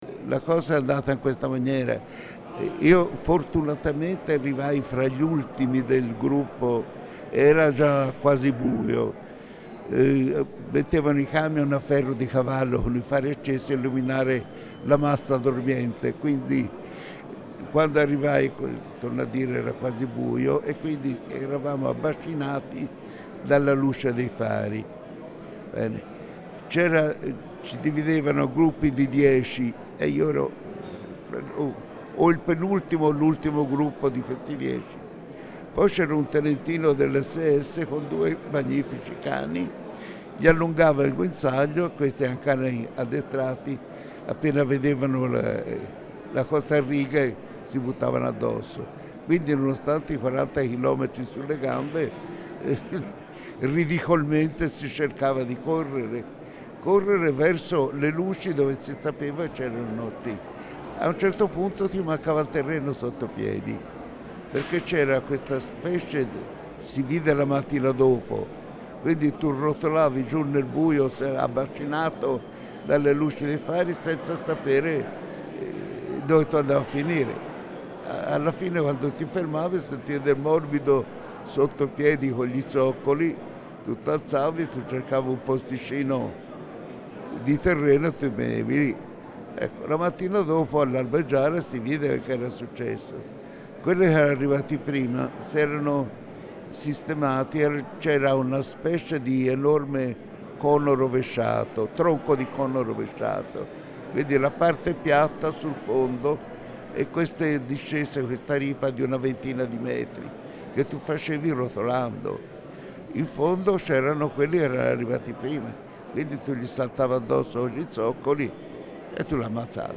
Anzi, come dice col suo accento toscano dugento prigionieri.